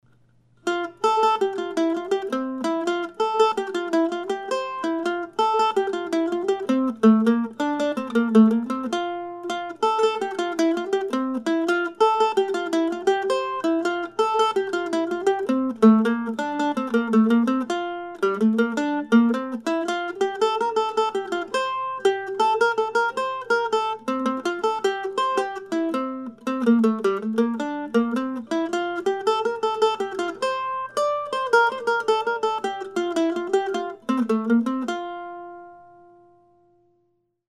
Early in 2016 I started writing short pieces modeled after the Divertimentos that James Oswald composed and published in the 1750s in London.
I've been playing them before or after Oswald's own pieces during my solo mandolin coffee house gigs this year and now my plan is to turn them into a small book that I intend to have available at the Classical Mandolin Society of America annual convention in Valley Forge, early next month.